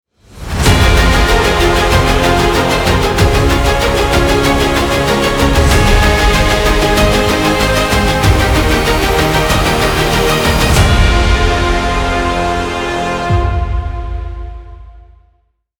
Music Ringtones